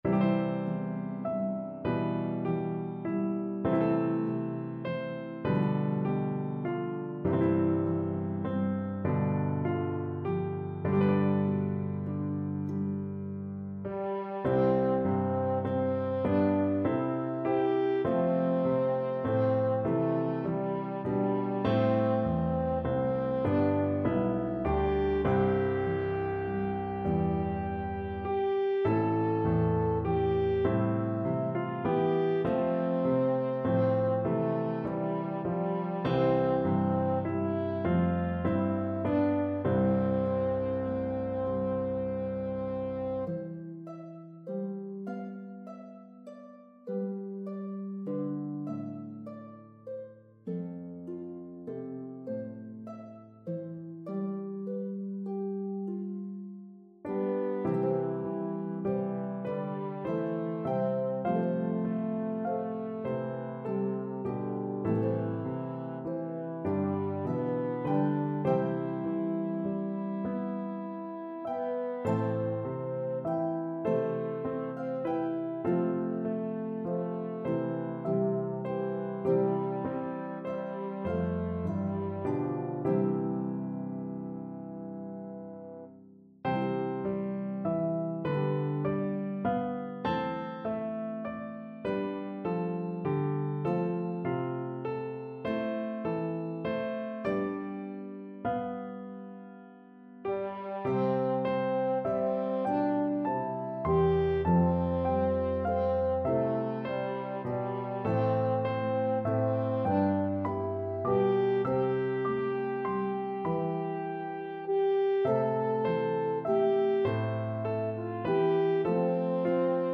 A meditative Appalachian melody